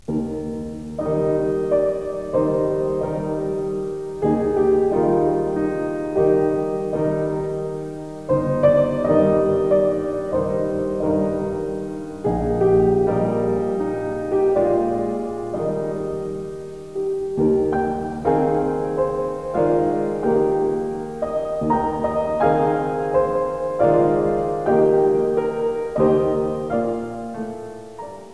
Das hier vorgestellte Intermezzo op. 117/1 erinnert zwar an die langsamen Sätze seiner Jugendsonaten, der Umstand aber, daß das Intermezzo über ein Schottisches Volkslied ( Wiegenlied einer unglücklichen Mutter aus Herders Volksliedern) geschrieben wurde, verweist auf den späteren Brahms.